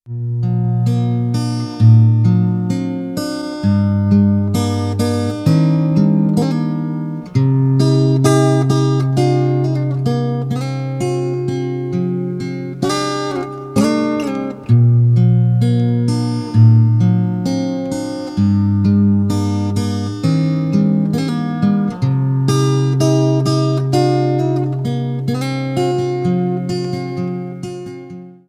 гитара , поп
без слов